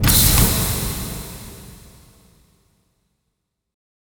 stabilizers.wav